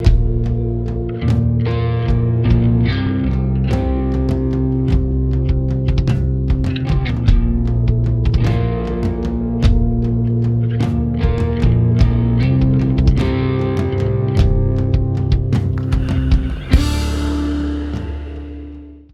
These samples were captured by passing the audio clips out then back in to the HD96, illustrating the bandwidth increase in both the inputs and outputs.
Stock Digidesign HD96